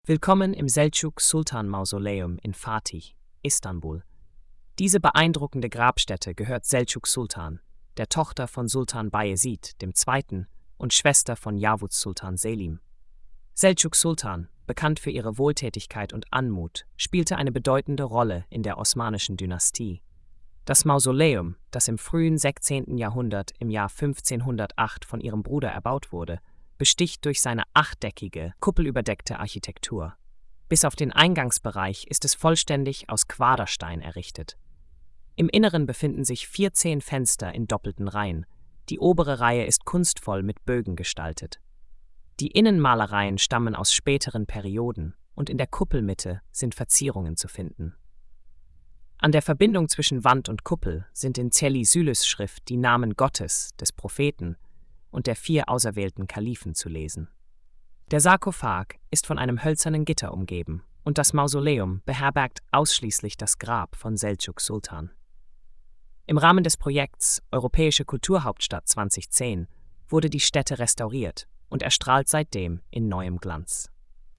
Audio Erzählung: